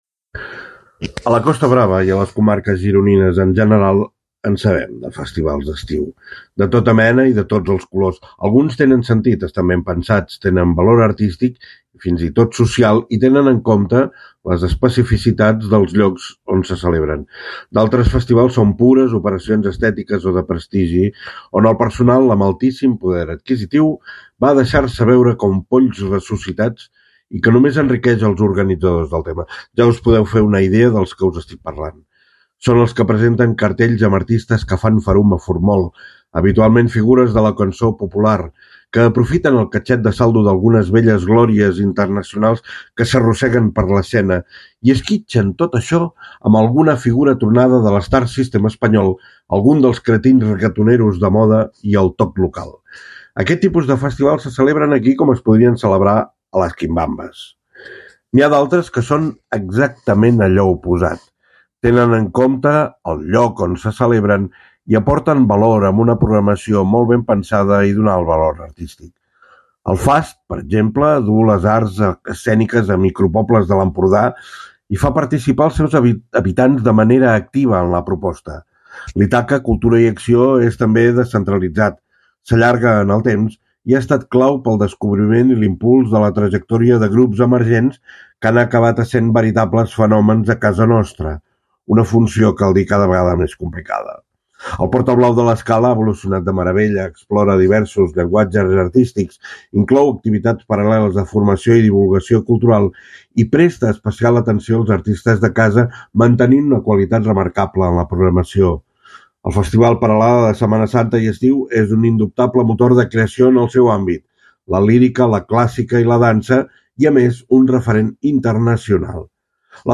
Francesc Ferrer, president de l’Entitat Municipal Descentralitzada de l’Estartit: